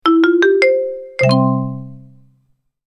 Marimba Success Sound Effect
A short marimba music phrase, perfect as a positive winning tone for games, apps, logos, and TikTok videos. Level up sound.
Genres: Sound Logo
Marimba-success-sound-effect.mp3